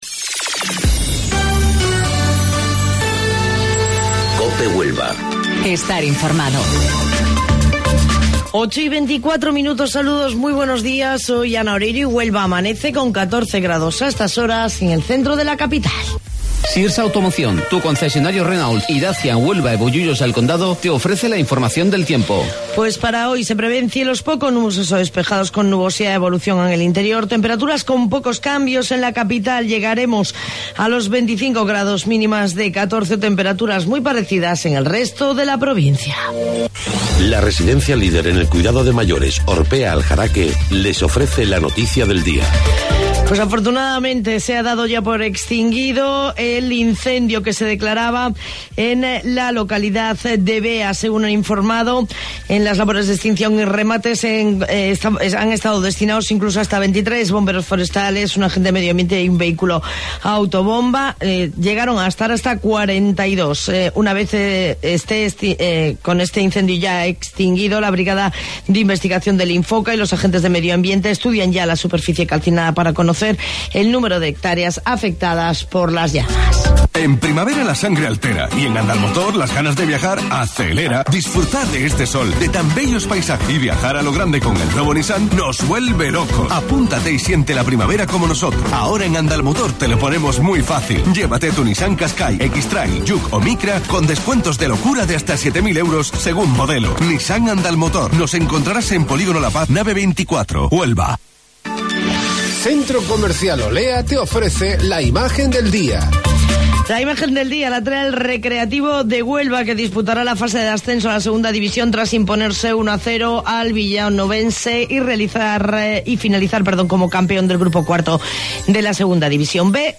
AUDIO: Informativo Local 08:25 del 20 de Mayo